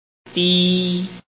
臺灣客語拼音學習網-客語聽讀拼-海陸腔-單韻母
拼音查詢：【海陸腔】di ~請點選不同聲調拼音聽聽看!(例字漢字部分屬參考性質)